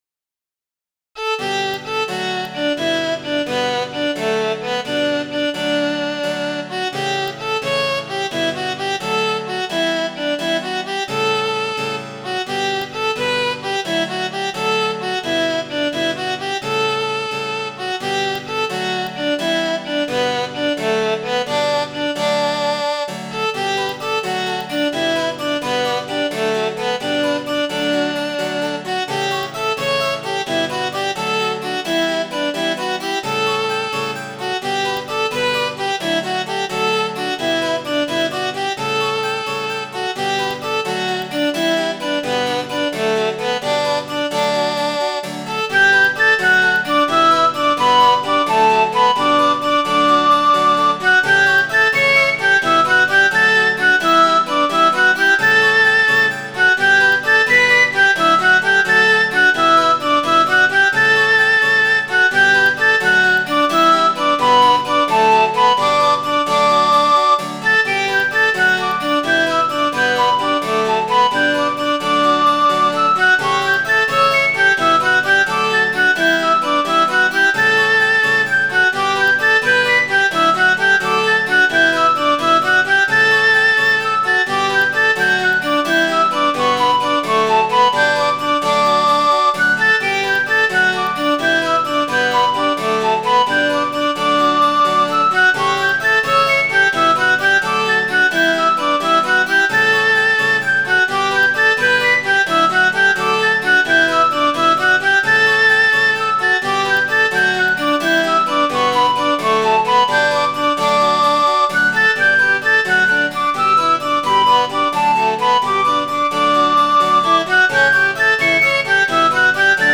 glasgow.mid.ogg